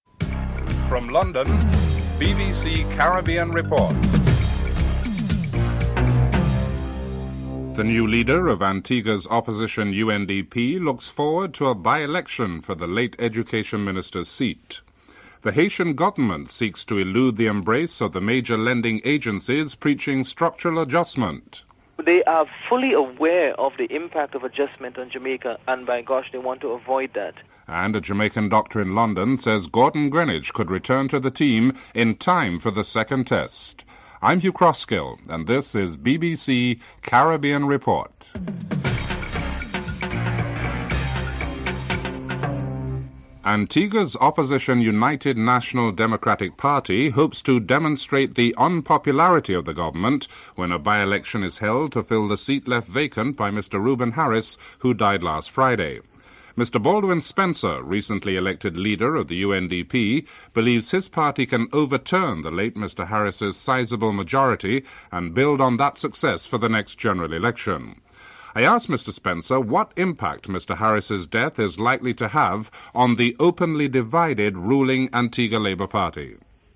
1. Headlines (00:00-00:42)